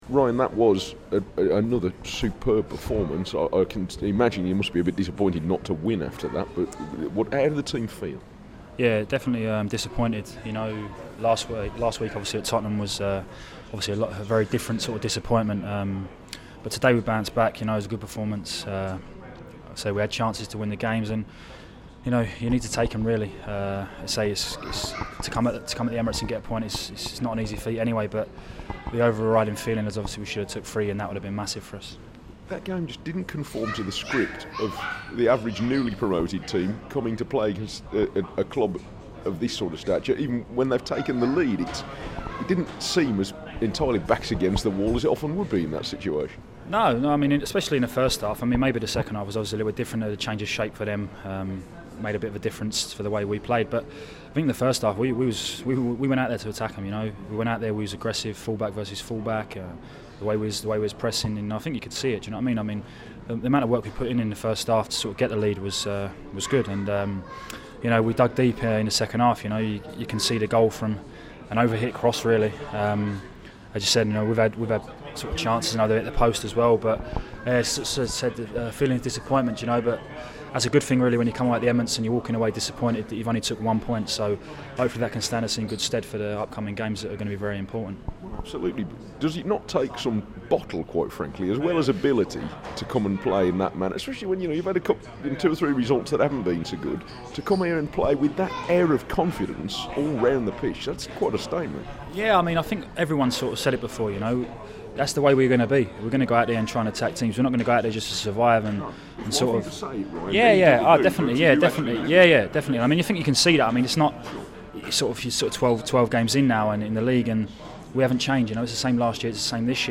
The Wolves defender talks to BBC WM following Wolves 1-1 draw at the Emirates.